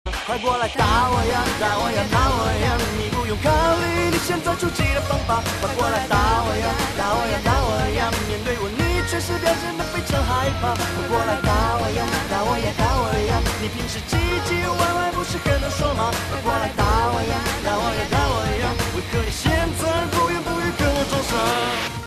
分类：搞笑铃声